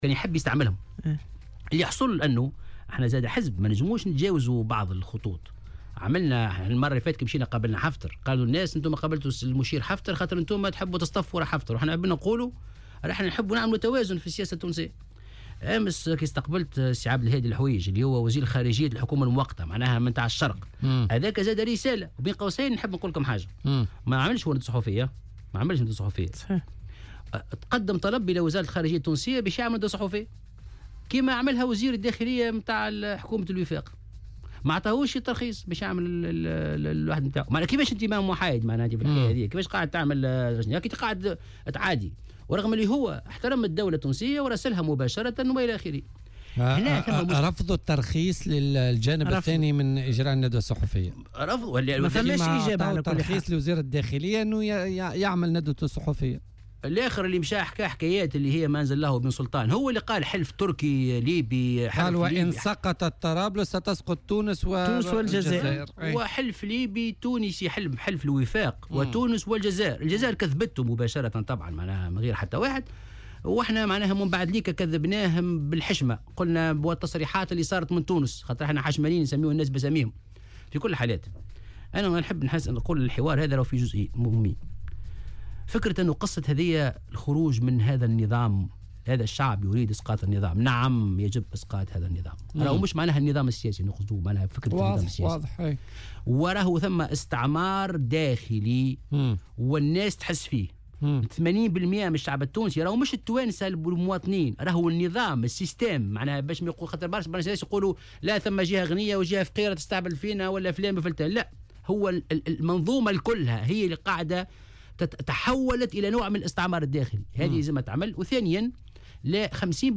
وأكد ضيف "بوليتيكا" على "الجوهرة اف أم" أنه تقدم بطلب لوزارة الخارجية لعقد ندوة صحفية إلا أنه لم يتم الردّ عليه الى غاية أمس فيما تم التّرخيص لوزير الدّاخلية من حكومة الوفاق لعقد ندوة صحفية في تونس الأسبوع الماضي.